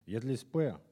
Collectif-Patois (atlas linguistique n°52)